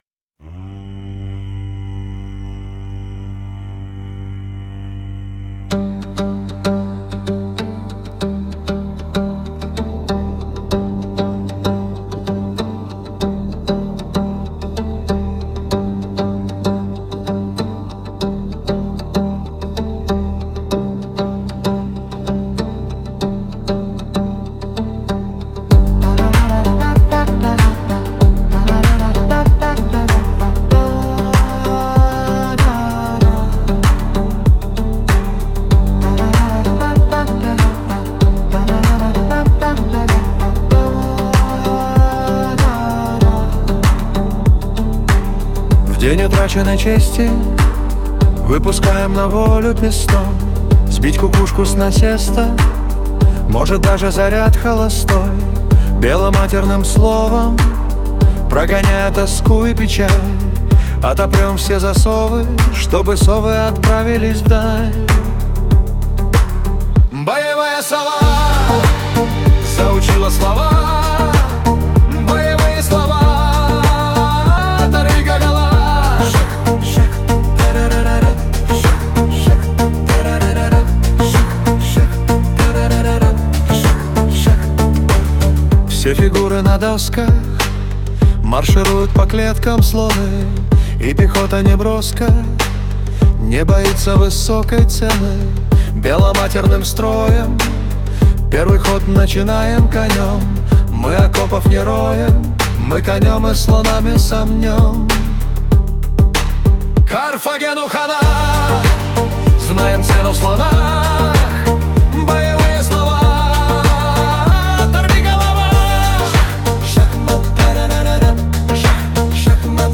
• Аранжировка: Collab
• Жанр: Фолк